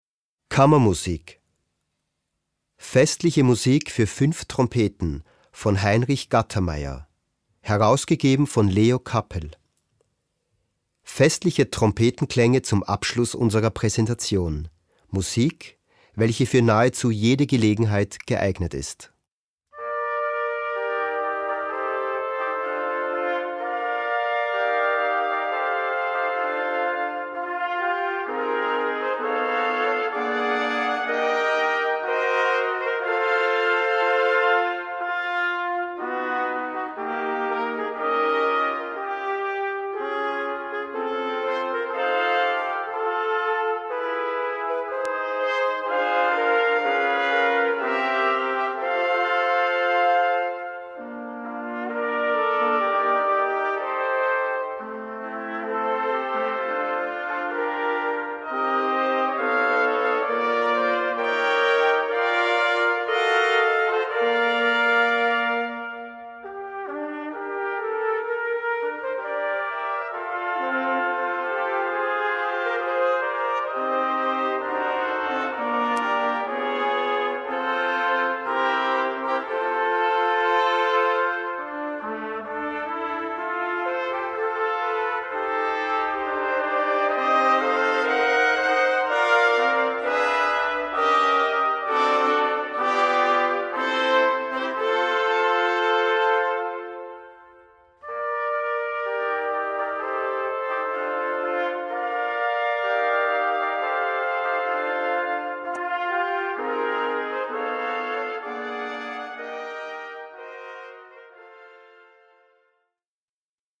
Gattung: Solo für fünf Trompeten und Blasorchester
Besetzung: Blasorchester
Festliche Trompetenklängnge!